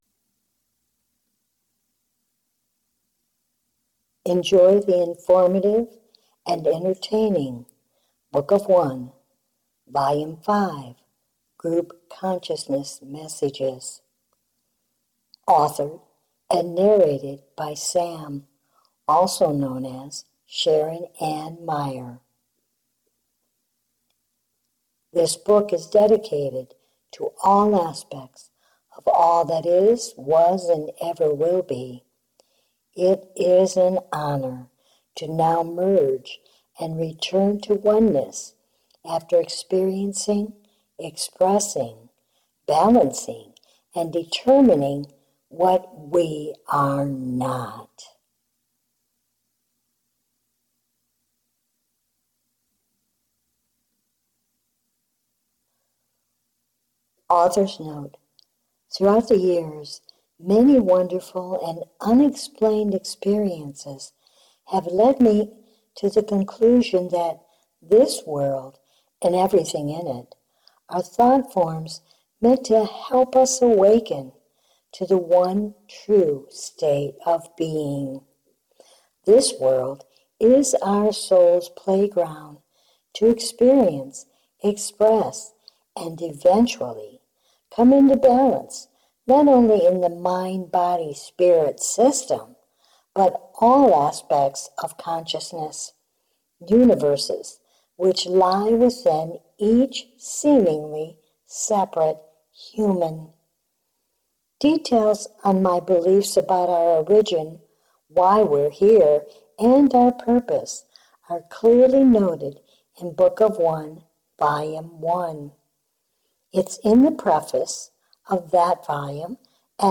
Audiobook Book of One :-) Volume 5